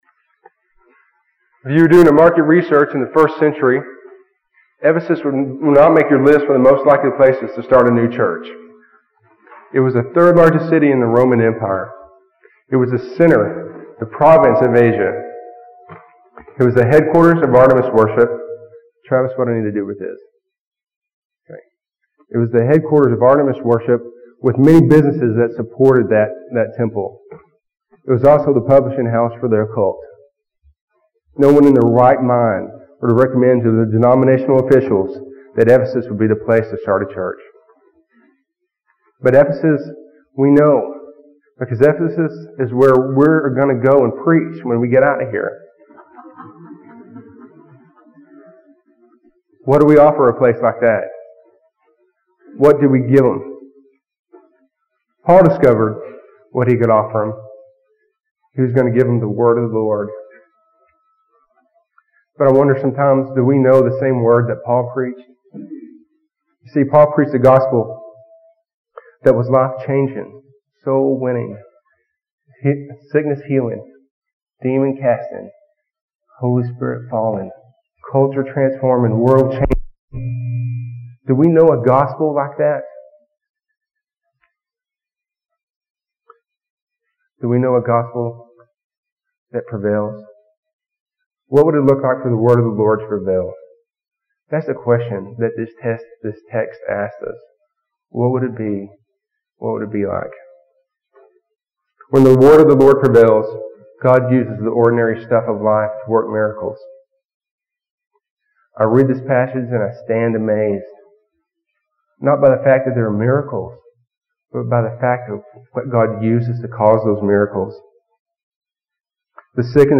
Chapel services, 1997
Sermons